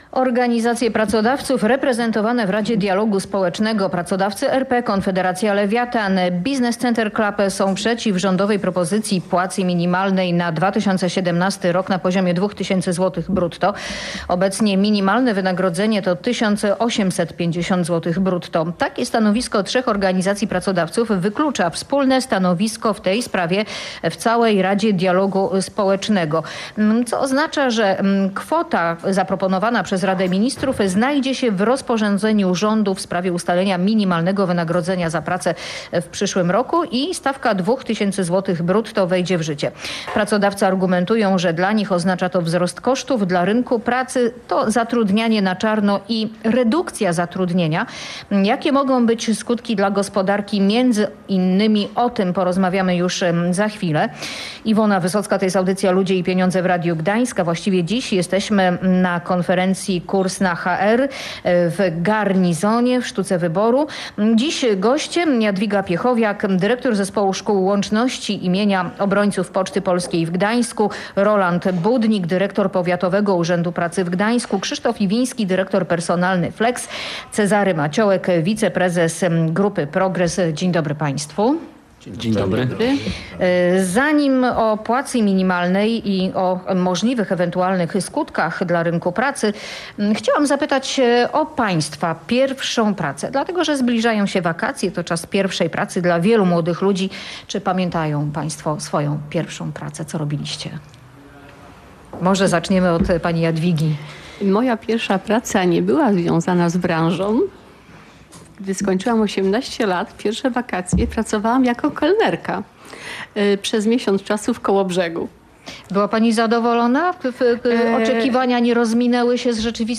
Jakie mogą być skutki tej decyzji dla polskiej gospodarki – rozmawiali o tym goście audycji Ludzie i Pieniądze w Radiu Gdańsk.